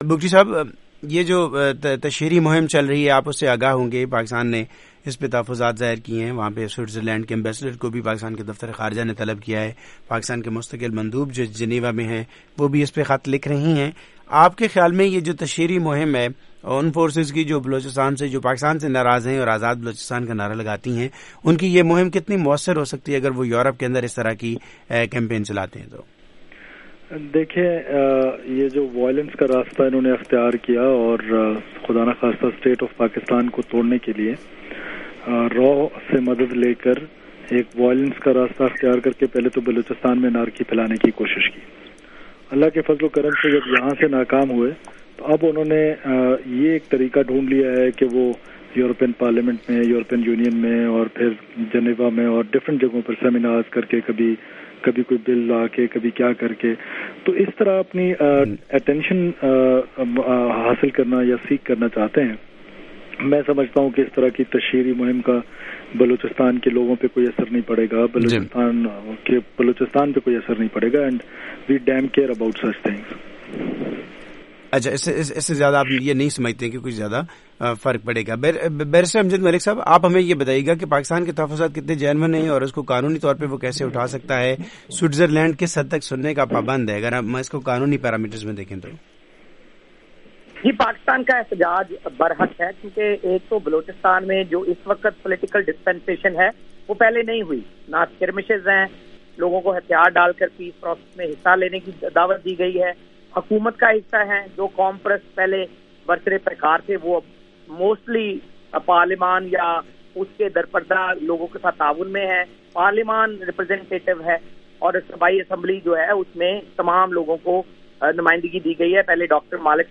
سرفراز بگٹی کی وائس آف امریکہ سے بات چیت سننے کے لئے درج ذیل لنک کلک کیجئے: